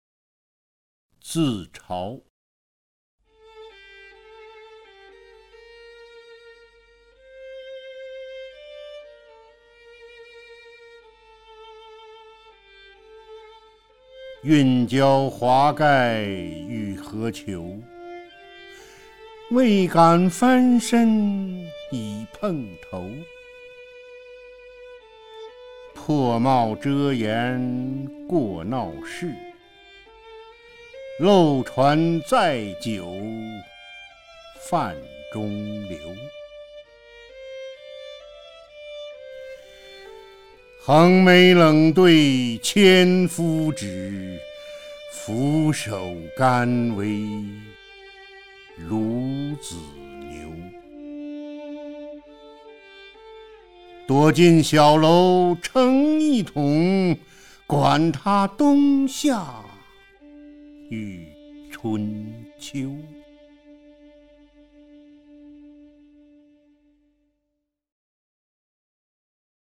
[3/8/2010]李默然配乐朗诵鲁迅作品《自嘲》(192K MP3) 激动社区，陪你一起慢慢变老！